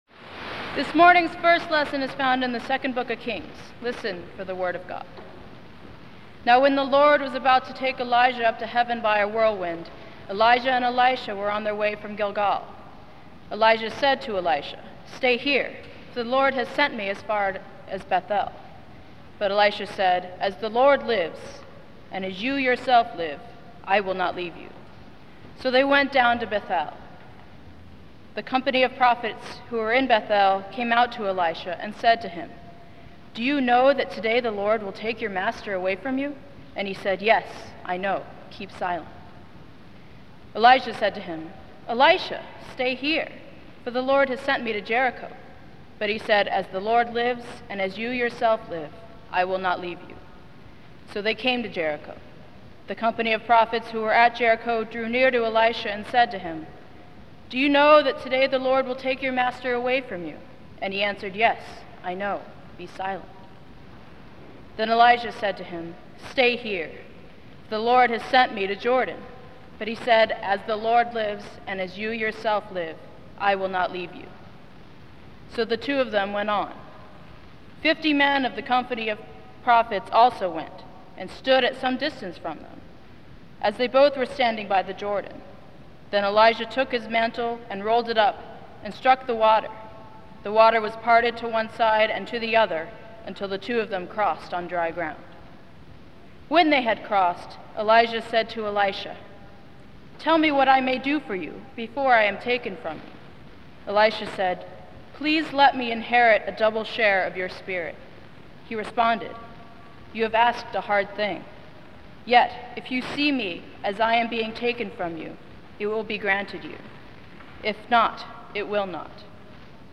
The original recording has lots of hiss and crackle, diminished here as much as possible.